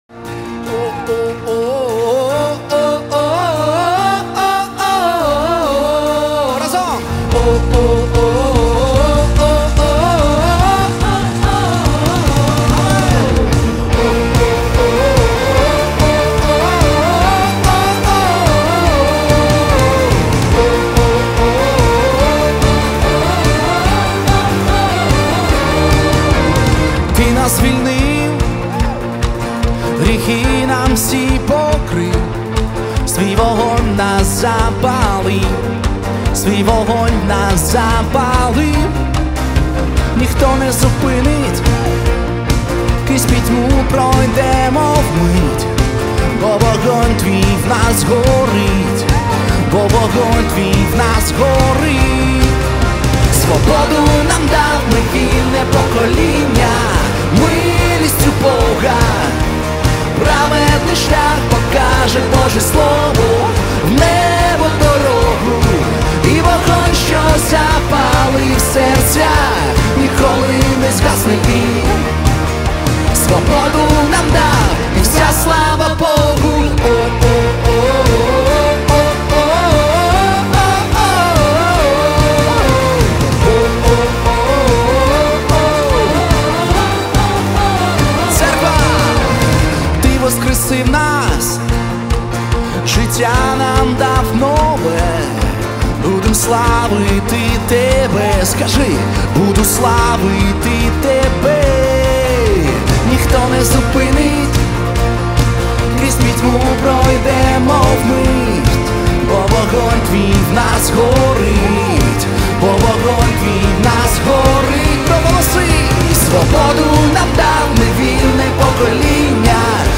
1103 просмотра 835 прослушиваний 22 скачивания BPM: 130